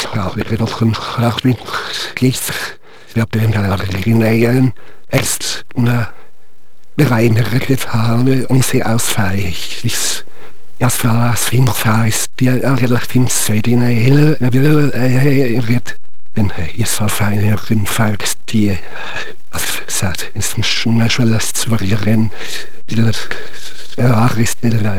• Ein Lehmloch, in welches man sein Kopf reinsteckt, und dann riecht man Erde und Heu und hört dem Gebrabbel von David Attenborough und Werner Herzog zu. Gebrabbel hört sich so an: